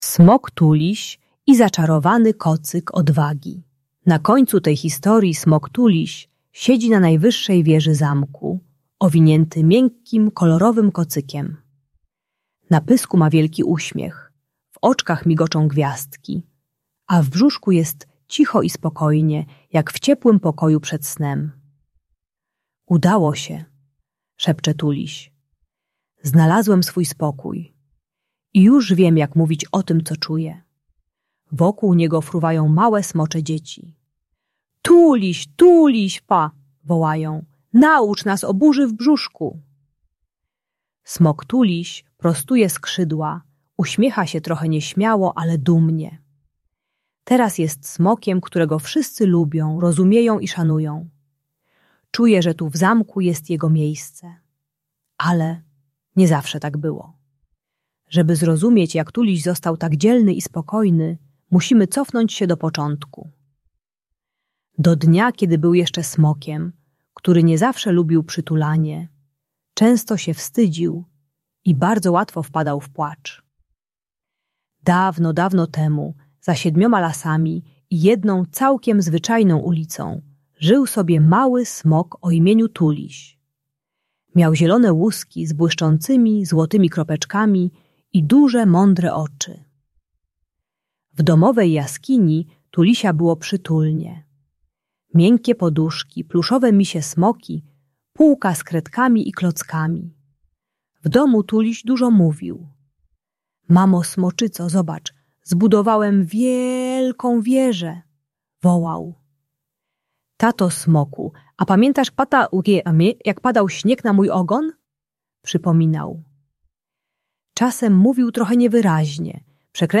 Smok Tuliś i Zaczarowany Kocyk - Lęk wycofanie | Audiobajka